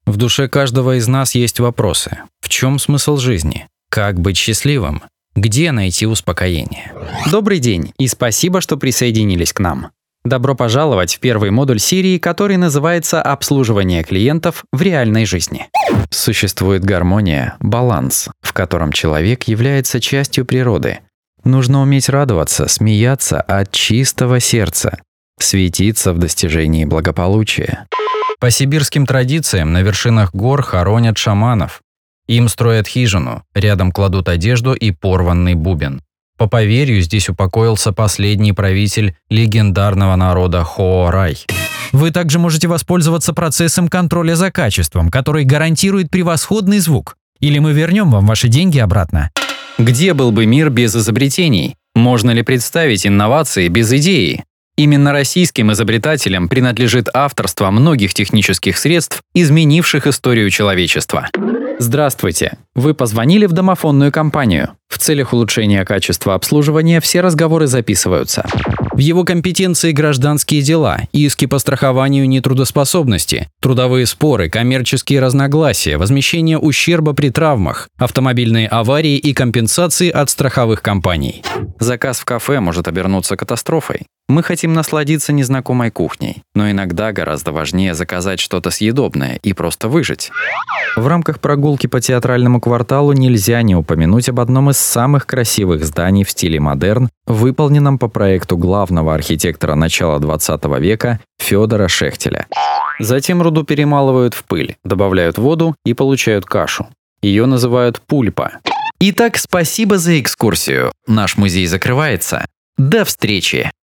Муж, Другая
Rode NT1 Rode NTG2 ISK BM-800 Октава МК-012 ART Voice Channel Presonus StudioLive 16.0.2 ESI Juli@